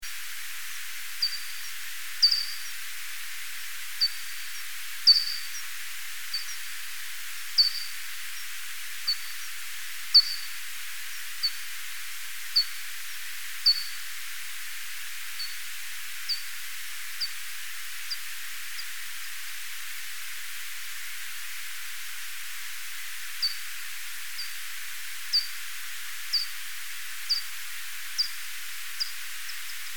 Sydpipistrell
Pipistrellus pipistrellus     Ppip
En annan inspelning gjord i Lunds botaniska trädgård. Emax även här kring 45 kHz men den har klart längre intervall mellan pulserna.